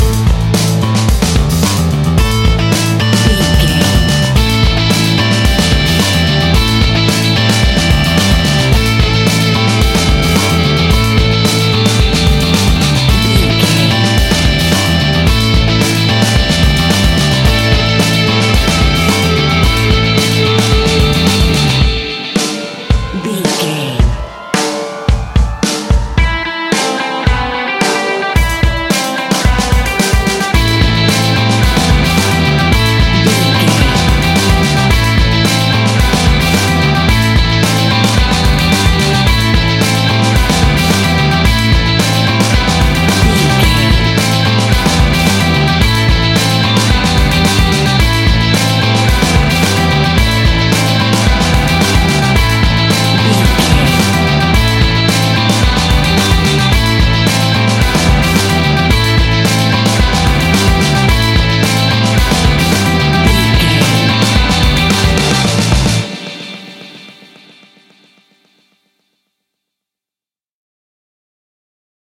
Ionian/Major
Fast
energetic
driving
happy
bright
electric guitar
bass guitar
drums
hard rock
blues rock
heavy drums
distorted guitars
hammond organ